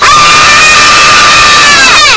scream.ogg